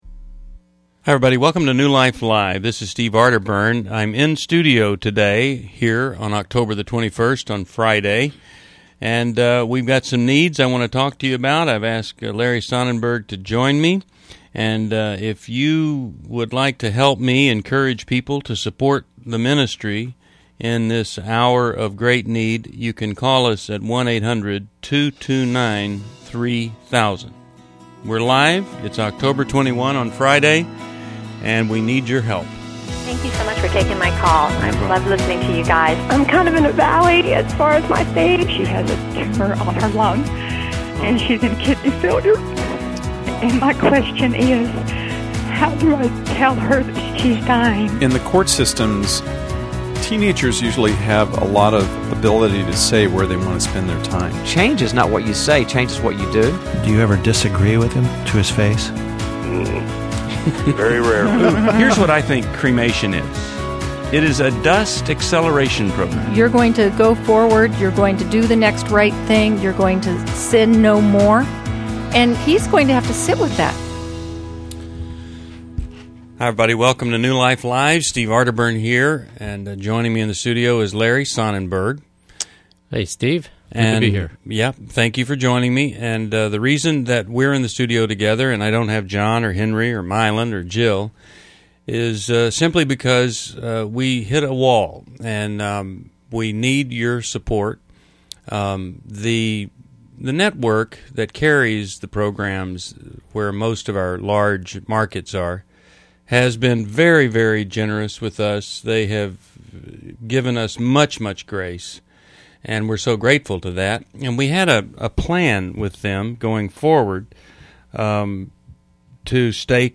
Caller Questions